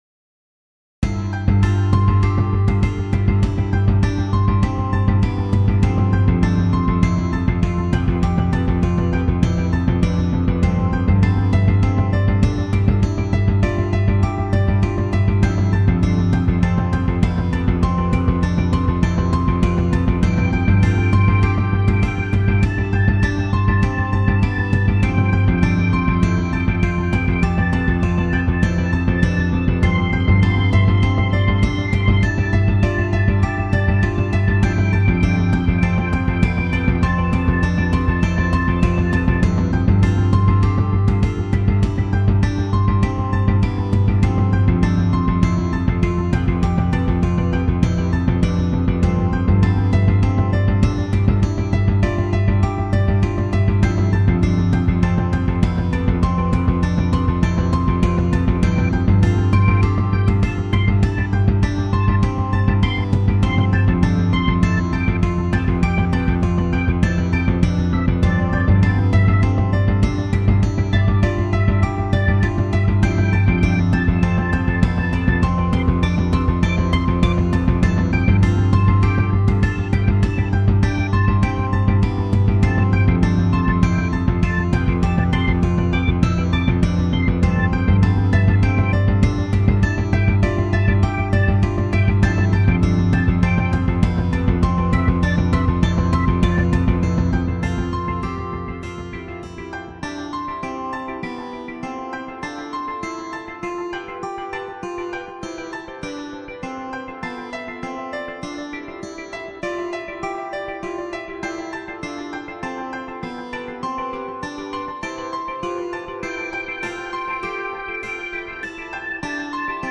Background › Comedy › Corporate